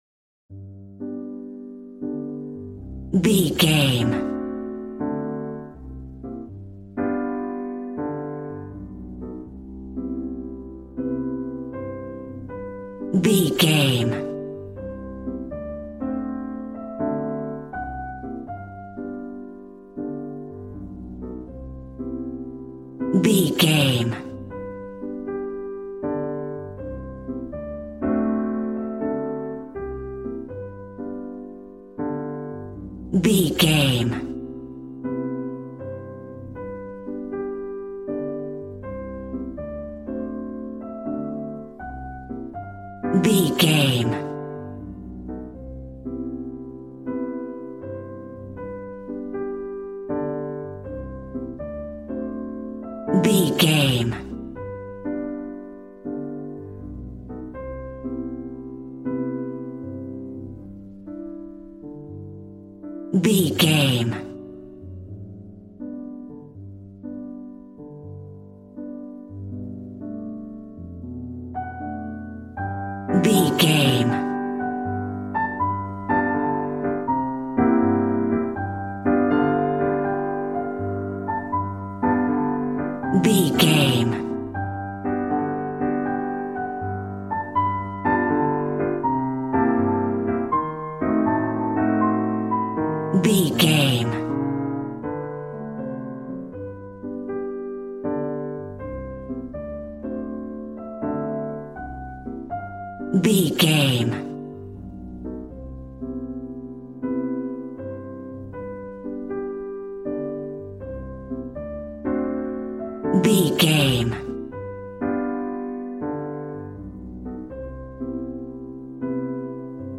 Smooth jazz piano mixed with jazz bass and cool jazz drums.,
Ionian/Major
piano
drums